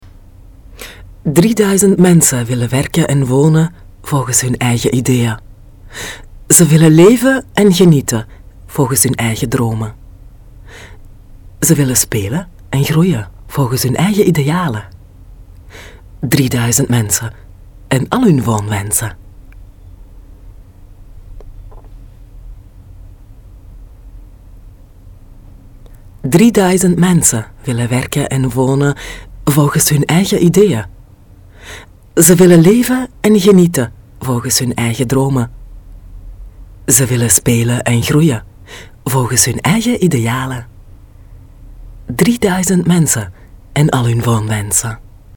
Sprecherin niederländisch aus Belgien, Weitere Sprachen: französisch und spanisch.
Sprechprobe: Werbung (Muttersprache):
dutch female voice over artist. experienced voice from Belgium, also French and Spanish possible. commercials, GPS,